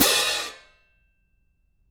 cymbal-crashshort_v1.wav